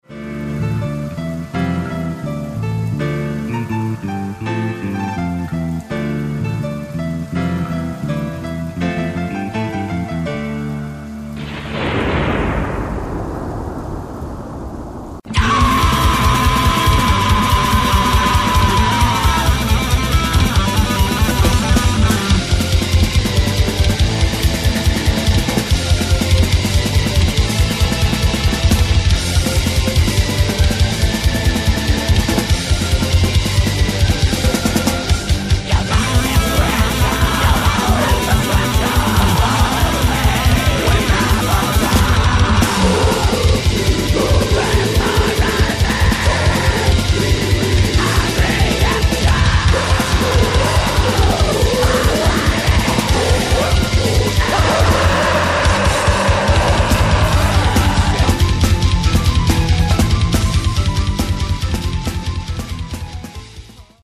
*Genre: Melodic Black Metal